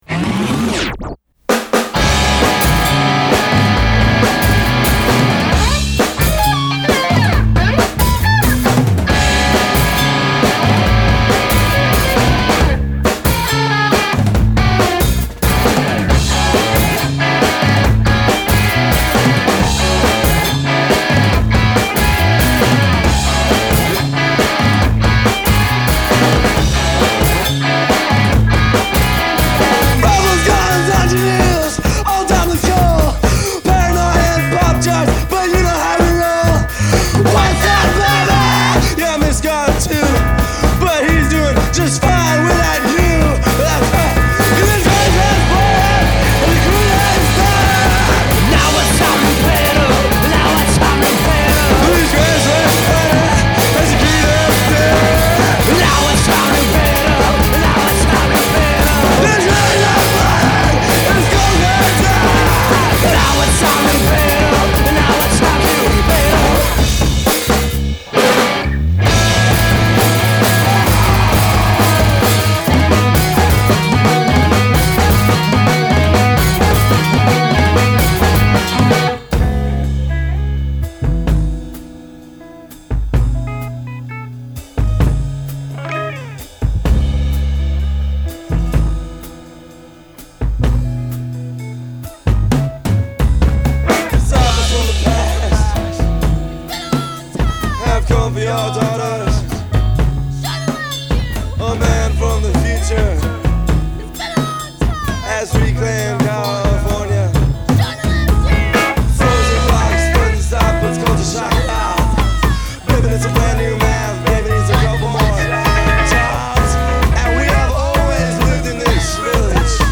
Athens post-rock